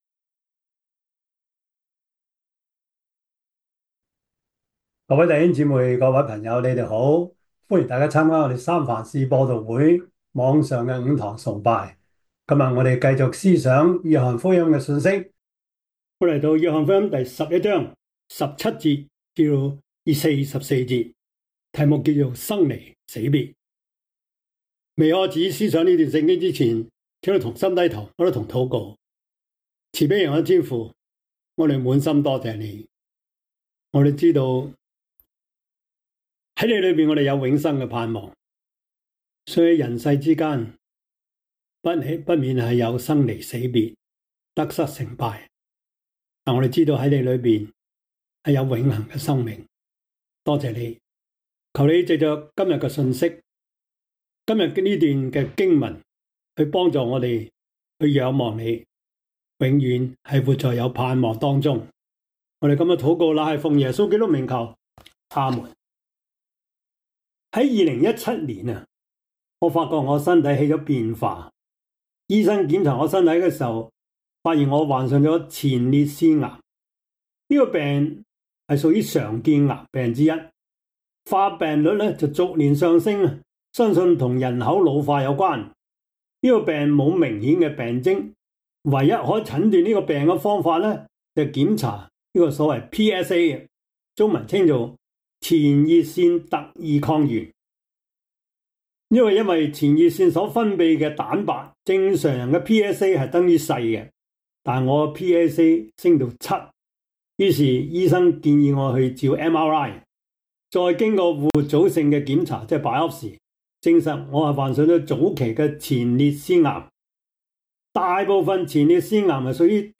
約翰福音 1:17-44 Service Type: 主日崇拜 約翰福音 11:17-44 Chinese Union Version
Topics: 主日證道 « 誰是自己人？